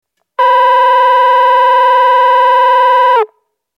１番線発車ベル
（長野より）   長野より（改札口付近）の電子電鈴での収録です。